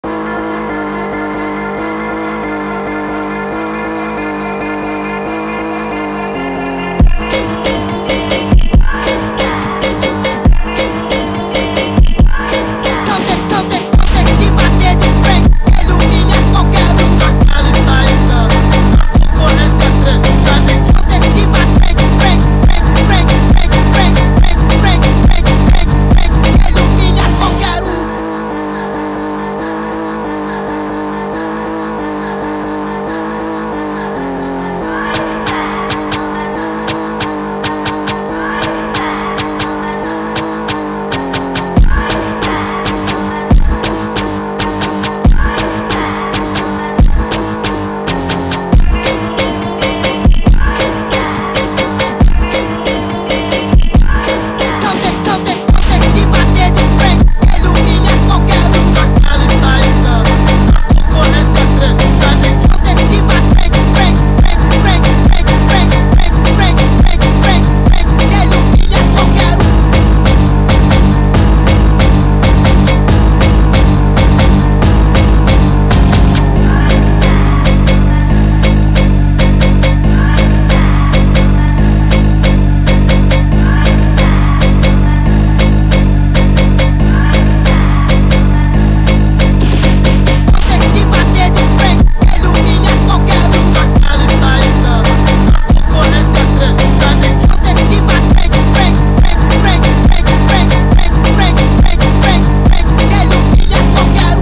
با ریتمی تند و جذاب در نسخه Sped Up
فانک